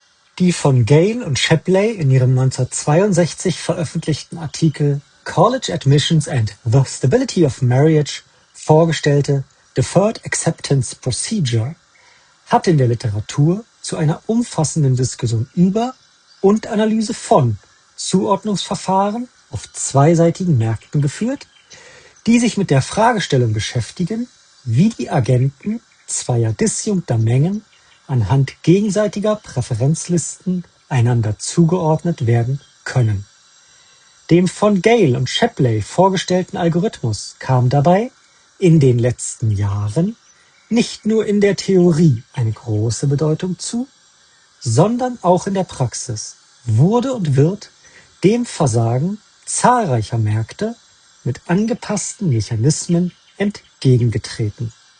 In der Praxis filtern die AirPods Pro 3 bei Telefonaten nicht die gesamte Umgebung heraus, was sich positiv auf den Klang der Stimme des Trägers auswirkt, dafür aber ein paar Störgeräusche mit überträgt. Der bei der Aufnahme präsente Straßenlärm ist so noch als Rauschen zu hören und auch das Vogelgezwitscher kommt immer wieder durch.
Dafür klingt die Stimme des Trägers der AirPods Pro 3 natürlicher und weniger blechern als noch mit den AirPods Pro 2.
Apple AirPods Pro 3 – Mikrofonqualität